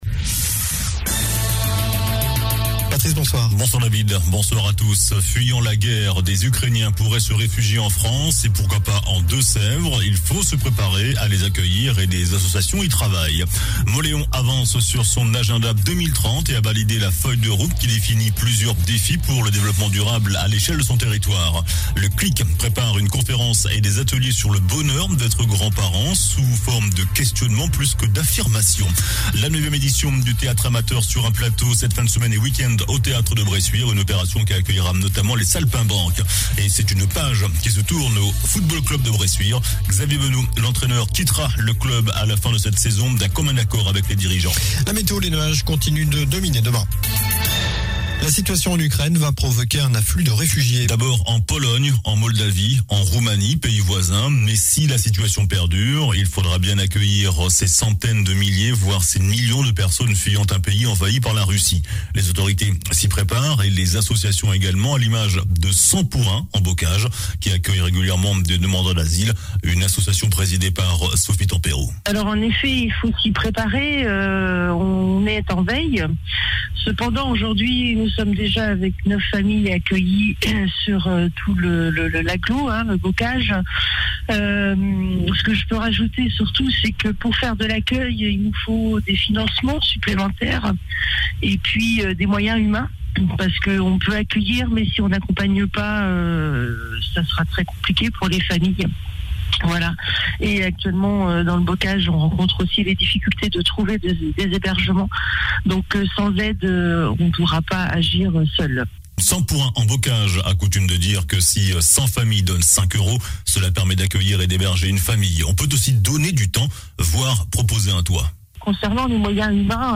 JOURNAL DU MERCREDI 02 MARS